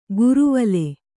♪ guruvale